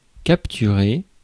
Ääntäminen
IPA : /siːz/